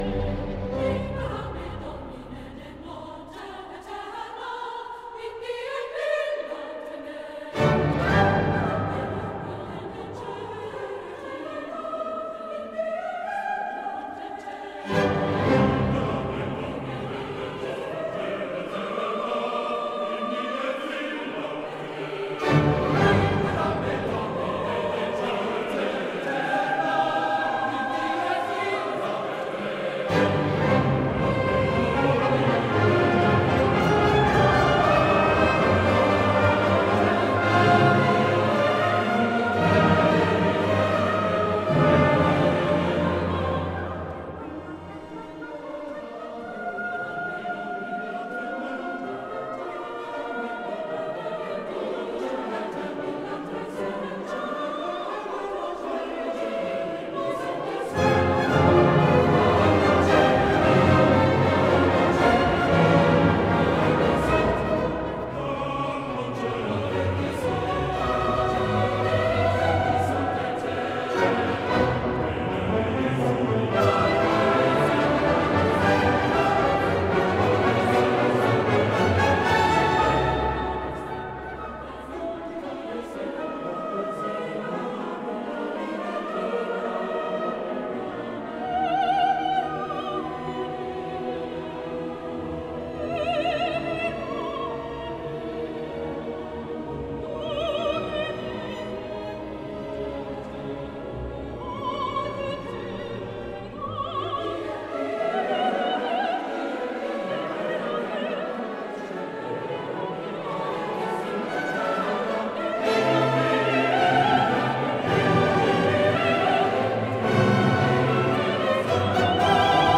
Dezember - MünchenKlang e.V. Chor und Orchester
Aufnahme vom 6.7.2014 in St. Margaret München
Der heutige Konzertausschnitt „Libera Me“ war von Verdi ursprünglich als Finale eines von 13 italienischen Komponisten gemeinsam verfassten Requiems komponiert worden.